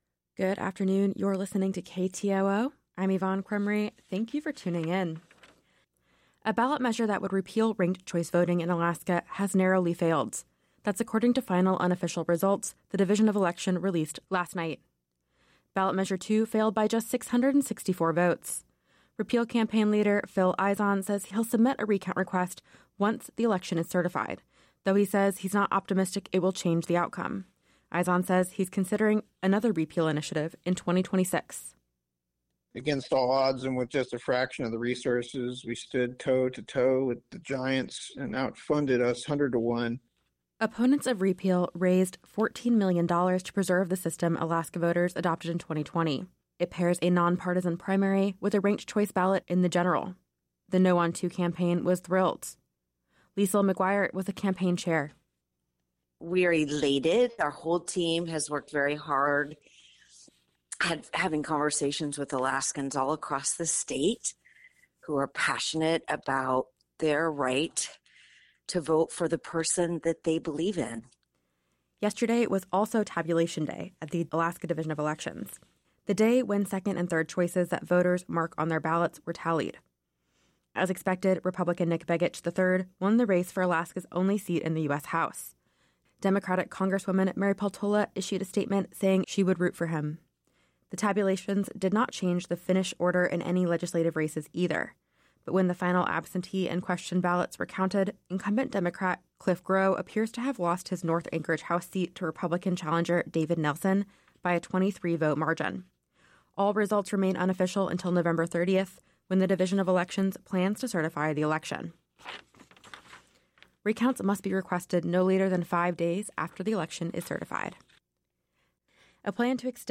Newscast — Thursday, Nov. 21, 2024